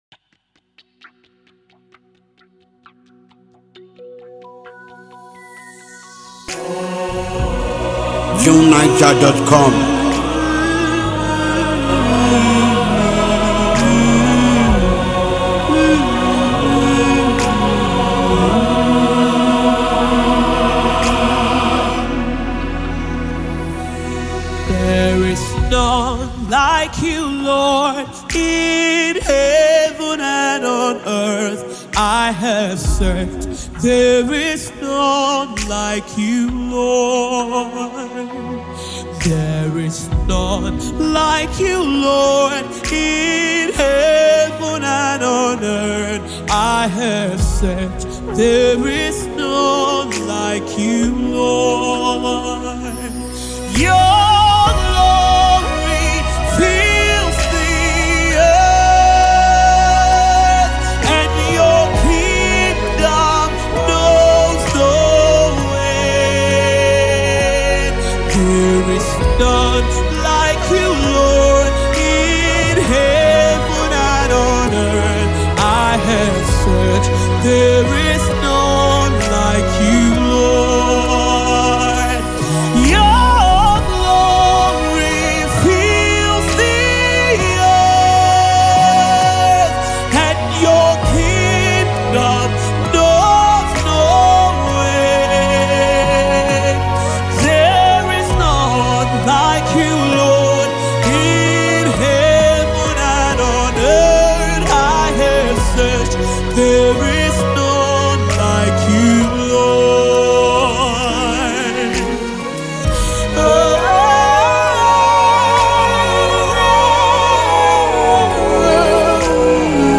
gospel
Anyone looking for soul-stirring music should have it.